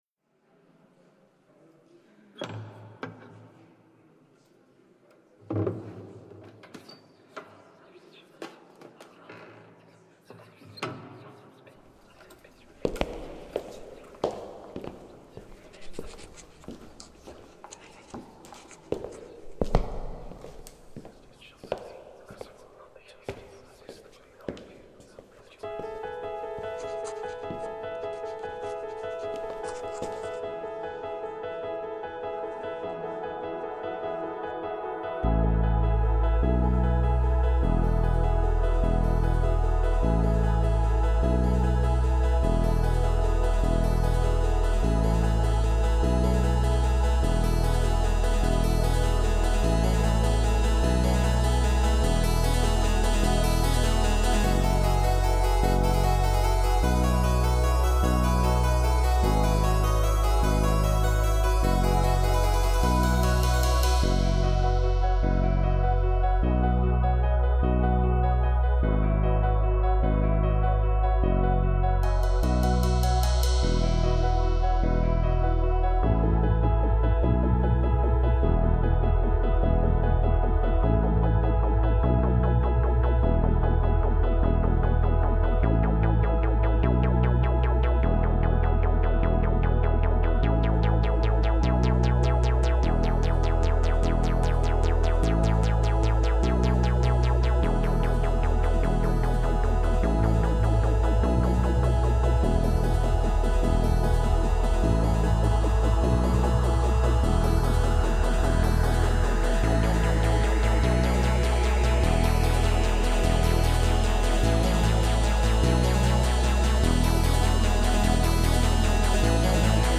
i ended up doing synth music (If more in line with ambient)
Sure you have a harpsichord, that's old timely right?